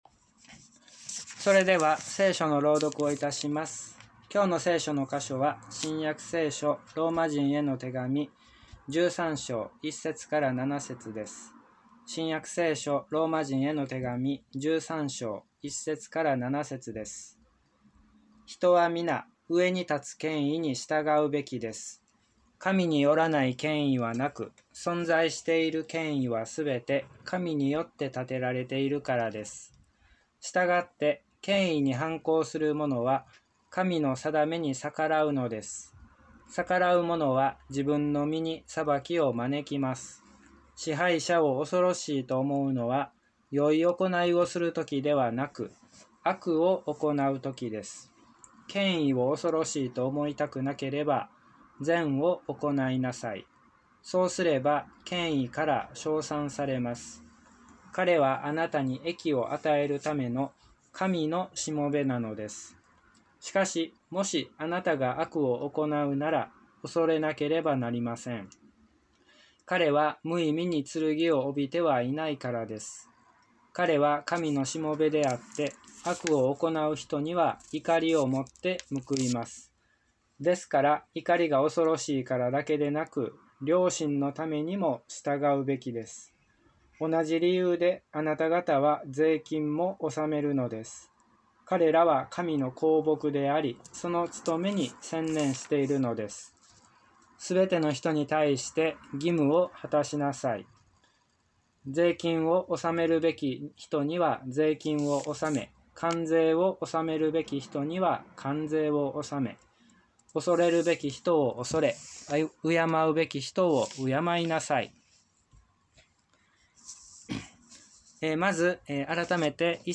礼拝説教から ２０２１年６月２７日